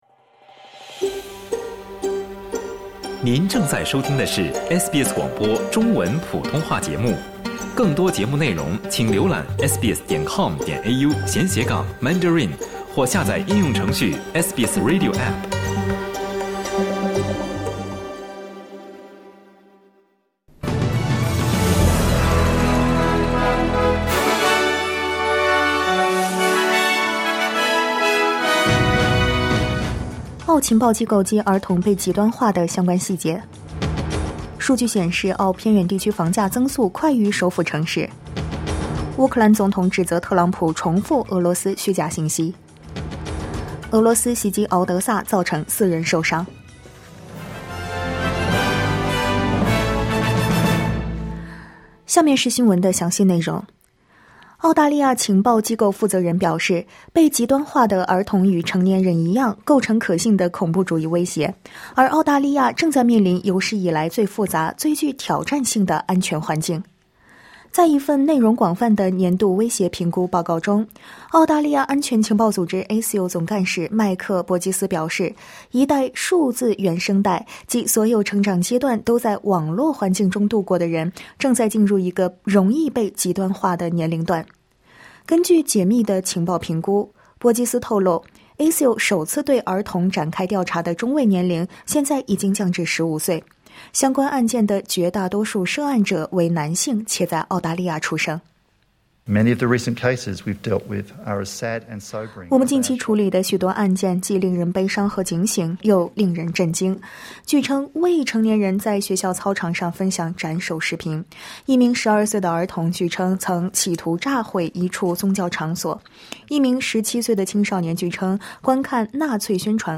SBS早新闻（2025年2月20日）